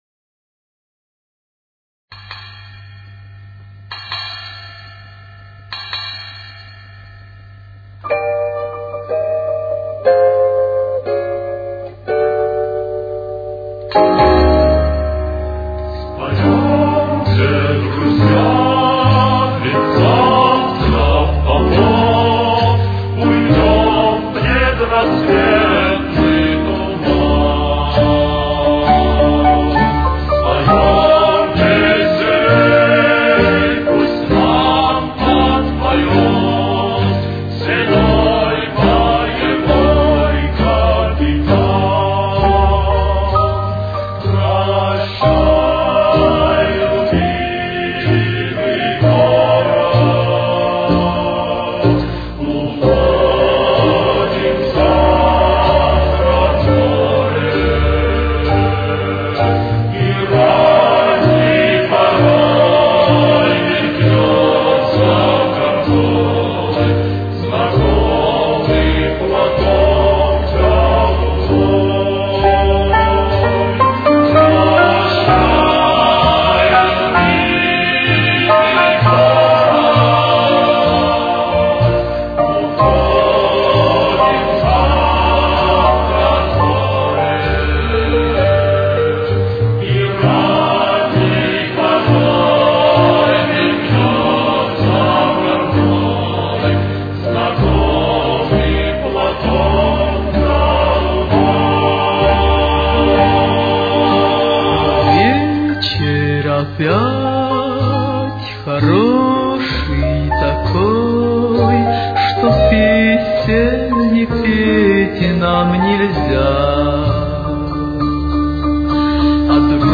с очень низким качеством (16 – 32 кБит/с)
Тональность: Соль минор. Темп: 61.